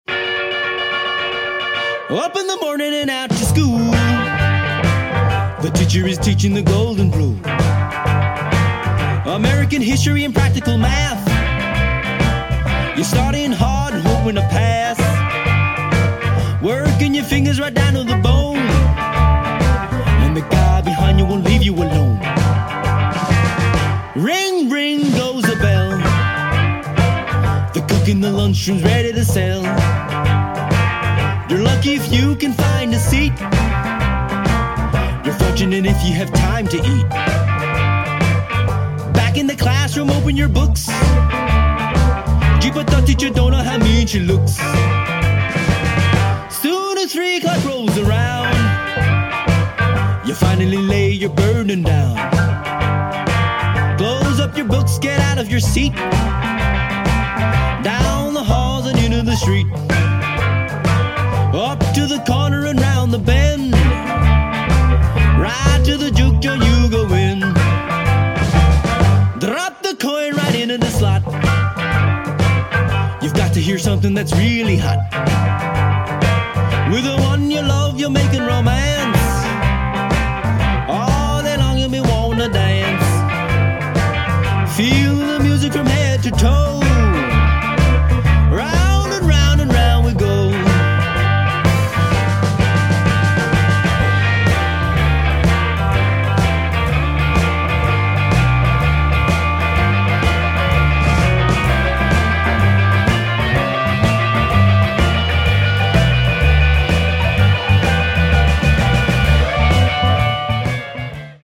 Blues Band
vintage dance tunes from the 1950’s